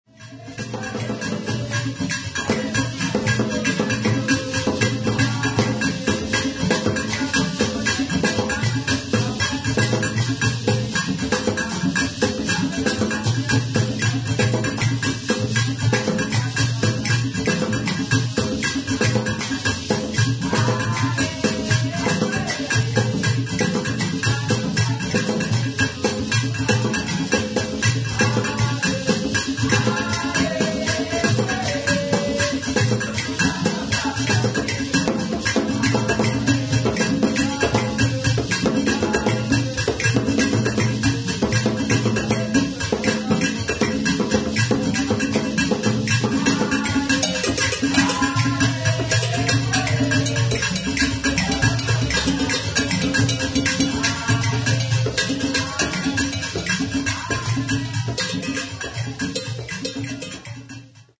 Kora
Le kora, ou hochet de calebasse, est le fruit du calebassier Lagenaria sp. Il est essentiellement utilisé par des femmes afin d'accompagner une danse, par exemple dans l'ensemble adowa où il permet de maintenir une rythmique constante.
La calebasse est évidée puis des pépins, des graines ou de petits cailloux y sont insérés. Les Dagomba l'appellent nmani et y intègrent des fruits séchés de l'okra (Abelmoschus esculentus), de manière à produire un son doux et plein.